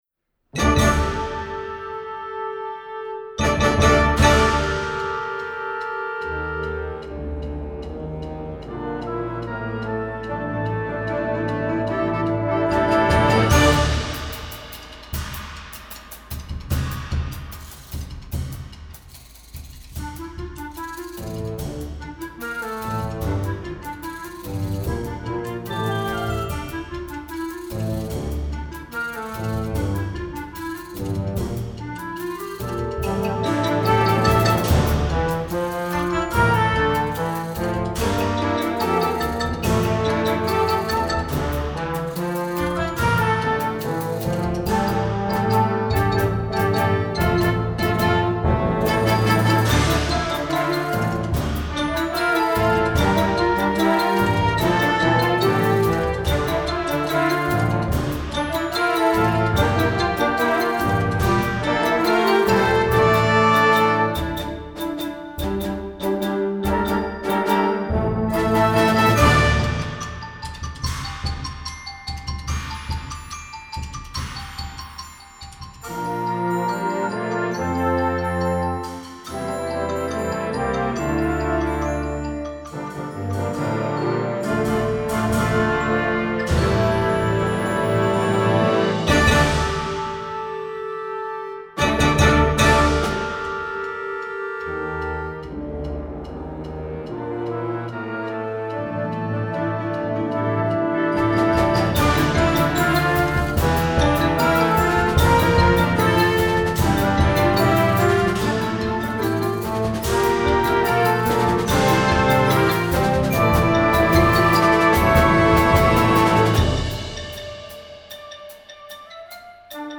Instrumental Concert Band Concert/Contest
Concert Band